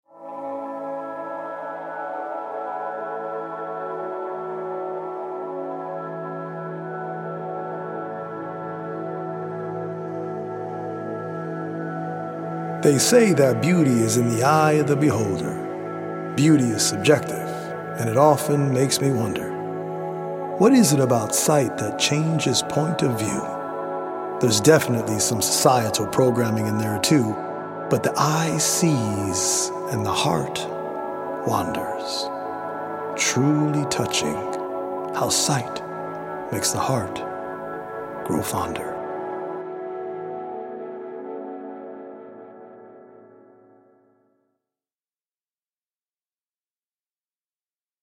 original poems
healing Solfeggio frequency music